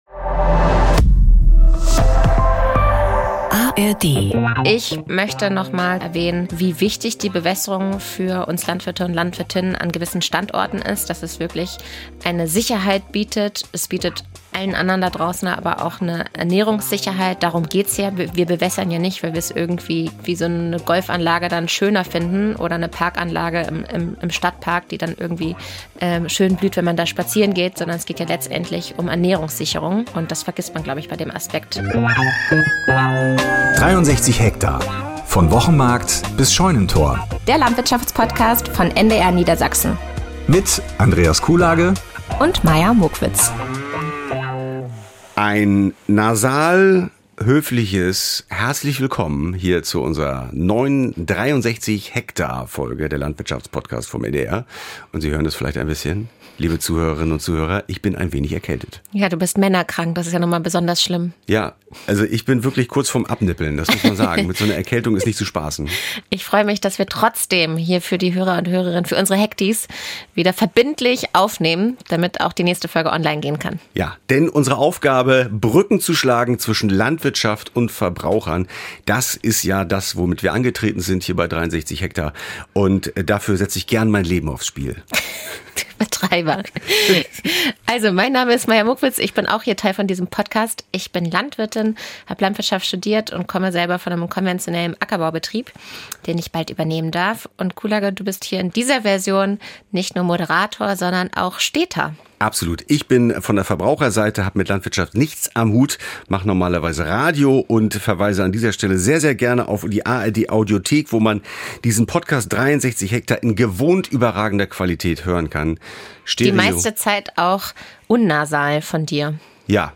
Lösungsansätze liefert auch eine Expertin vom Thünen-Institut.